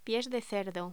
Locución: Pies de cerdo
voz